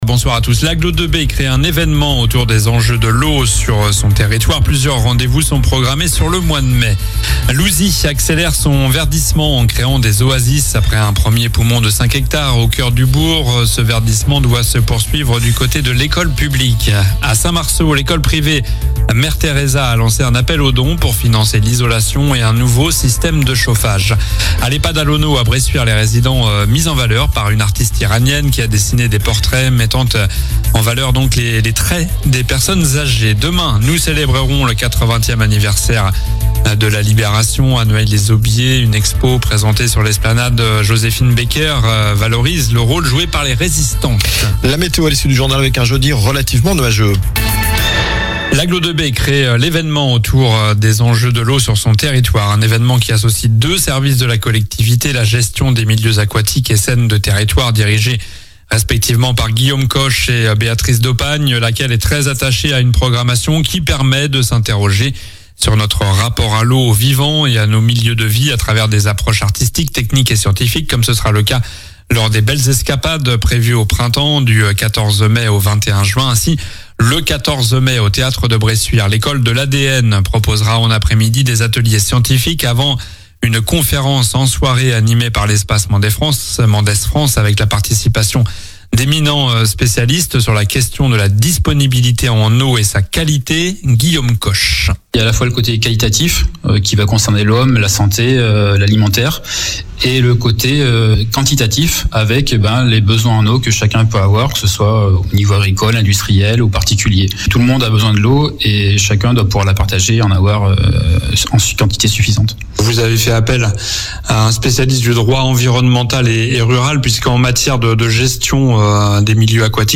COLLINES LA RADIO : Réécoutez les flash infos et les différentes chroniques de votre radio⬦
Journal du mercredi 7 mai (soir)